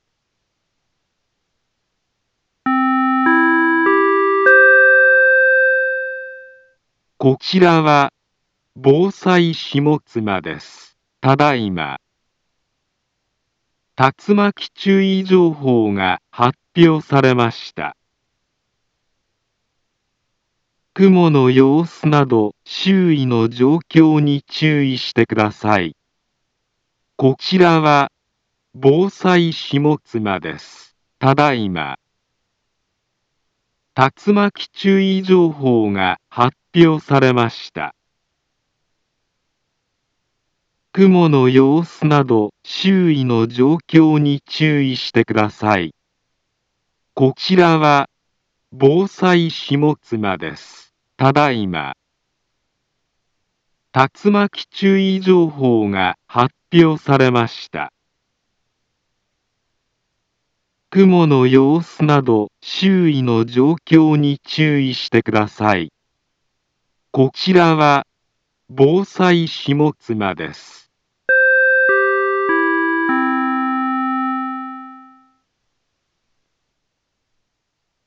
Back Home Ｊアラート情報 音声放送 再生 災害情報 カテゴリ：J-ALERT 登録日時：2023-07-11 18:09:49 インフォメーション：茨城県北部、南部は、竜巻などの激しい突風が発生しやすい気象状況になっています。